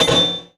SI2 METAL0EL.wav